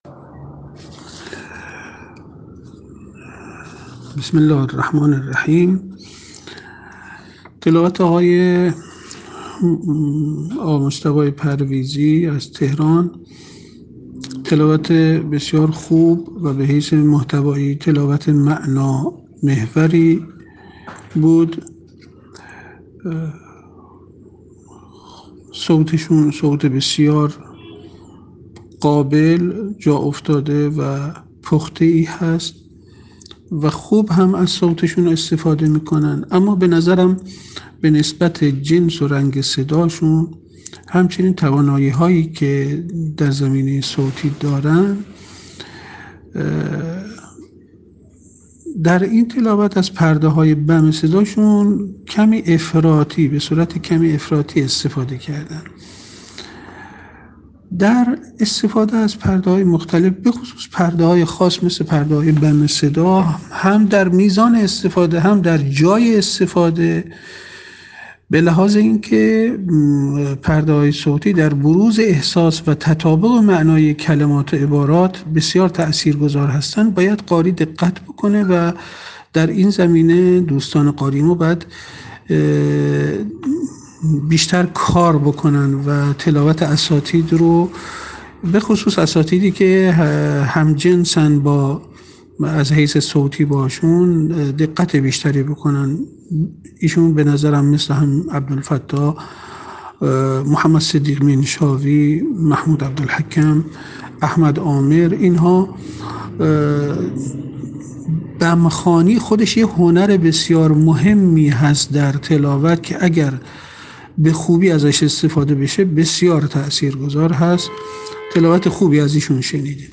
فیلم اجرای قاریان فینالیست در اولین شب مسابقات سراسری قرآن
صوت ایشان بسیار قابل، جاافتاده و پخته است و ایشان به خوبی از صوت خودشان استفاده می‌کنند.